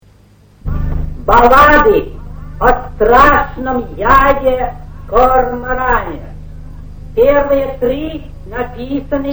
Записи 1951г, квартира Л.Ю.Брик: